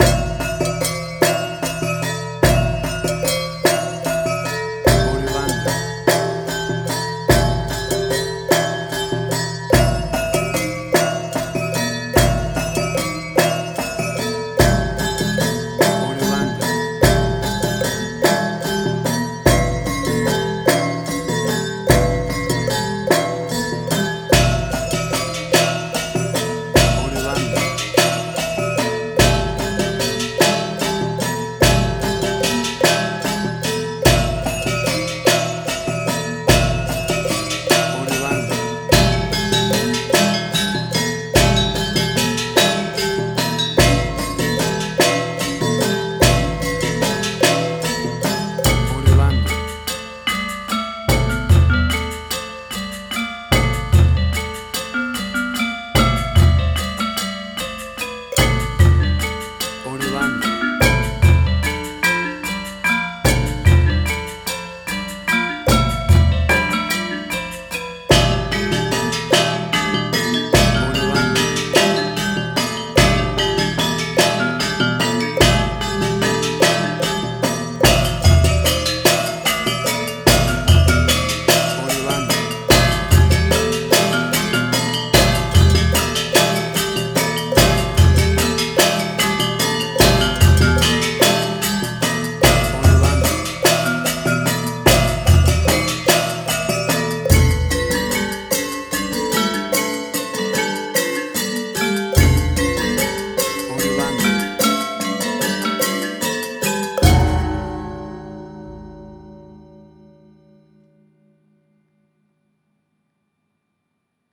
Gamelan Ethnic instrumental.
WAV Sample Rate: 16-Bit stereo, 44.1 kHz
Tempo (BPM): 148